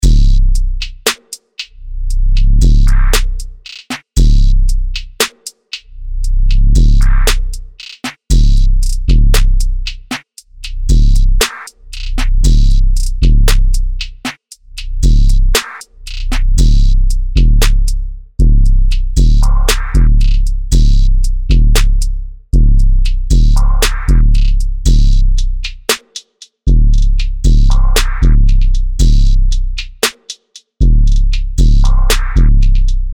アトランタスタイルの808ベースとトラップビートが弾けるリズムサウンドを解き放つ
・力強いトラップ・ビートとチューニングされた808が、磨き上げられた荒々しさを湛える
プリセットデモ